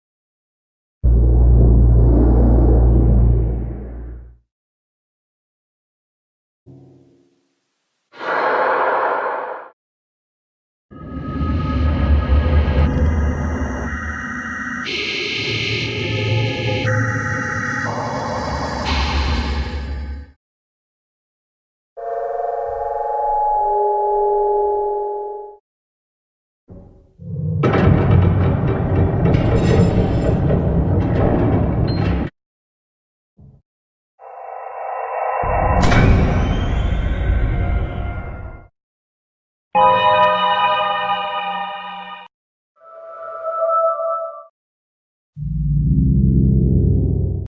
Minecraft cave sounds but there sound effects free download
Minecraft cave sounds but there some creepy creatures